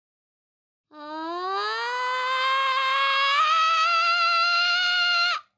yelling.ogg